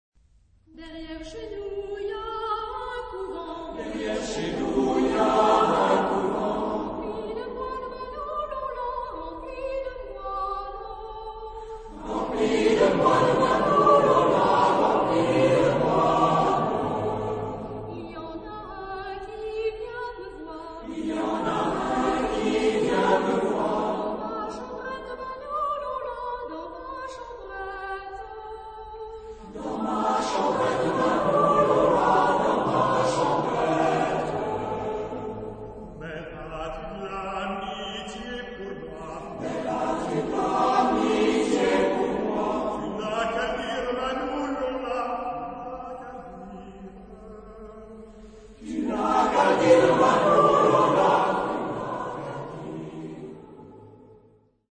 Genre-Stil-Form: Volkstümlich ; Liedsatz ; weltlich
Charakter des Stückes: lebhaft
Chorgattung: SATB  (4 gemischter Chor Stimmen )
Solisten: Soprano (1) / Ténor (1)  (2 Solist(en))
Tonart(en): a-moll